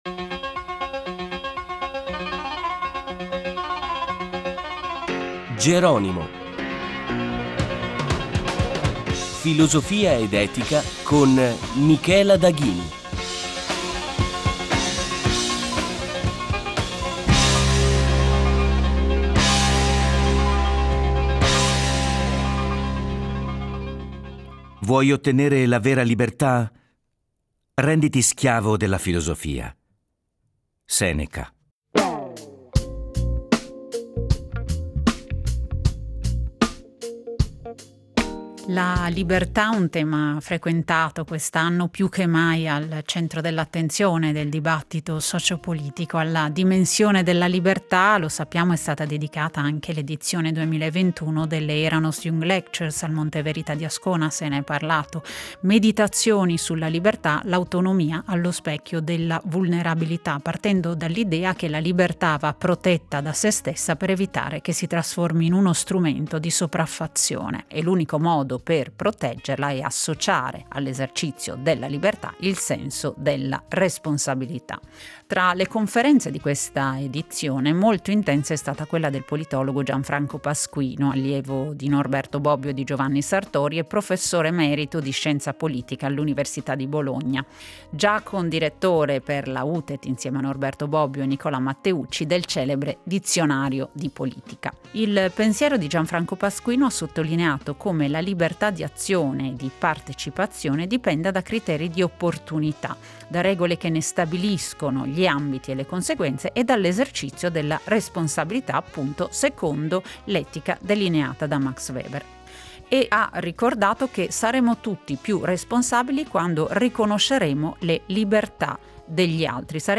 In questa puntata incontriamo il celebre politologo Gianfranco Pasquino , allievo di Norberto Bobbio e di Giovanni Sartòri e professore emerito di Scienza politica all’Università di Bologna, che ha ricordato come la libertà dallo Stato e dalle sue interferenze sia necessaria, ma possa, a determinate condizioni e secondo precise modalità, essere limitata.